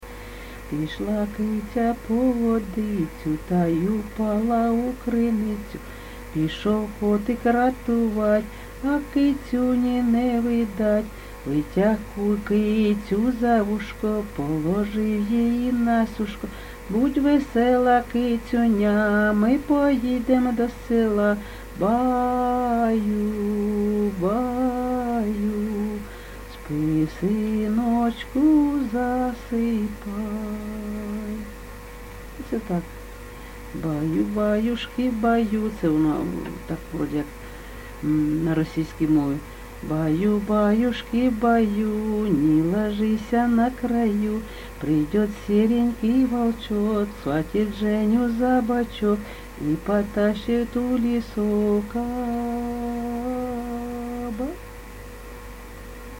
ЖанрКолискові
Місце записус. Серебрянка, Артемівський (Бахмутський) район, Донецька обл., Україна, Слобожанщина